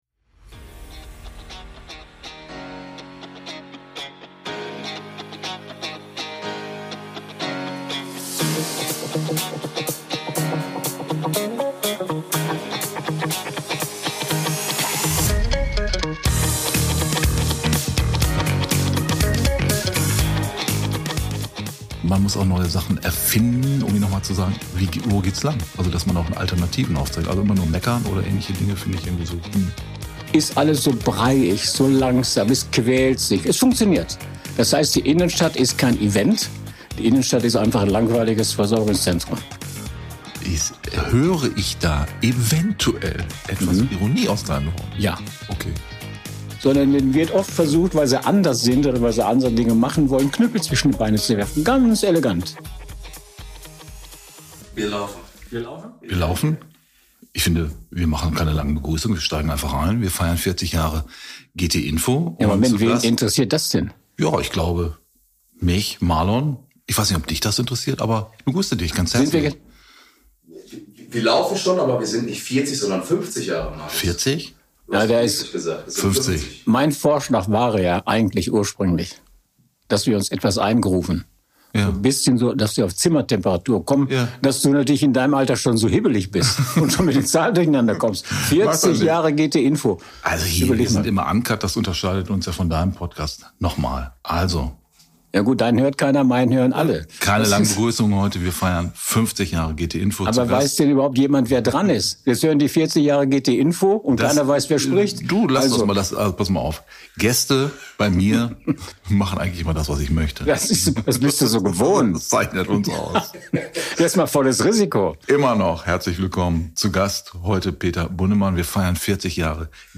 Zwei Podcaster machen aus diesem Anlass mal gemeinsame Sache.